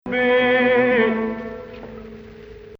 Maqam Rast
2nd Rast 5/8
finally, proper resolution 6-5